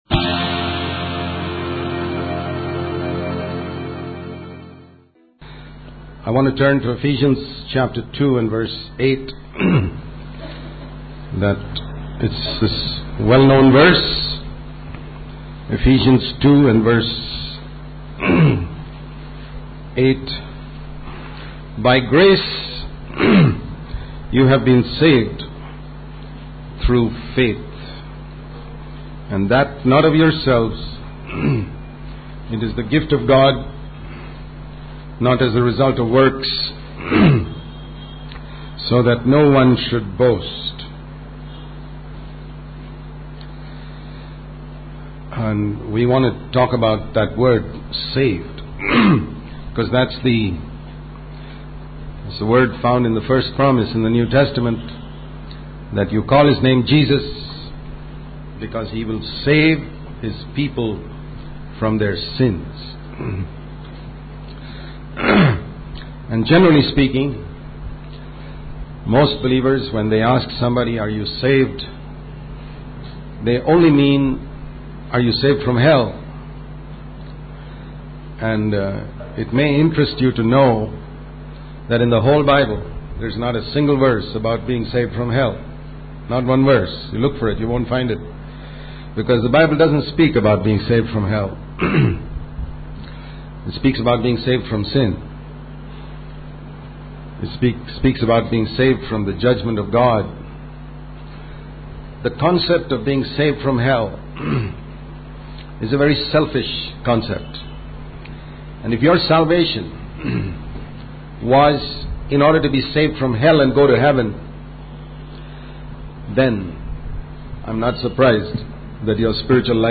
In this sermon, the speaker emphasizes the influence of Satan in various aspects of the world, including entertainment, music, medicine, and education. He urges listeners not to be deceived by the devil and to stand as a light in this world.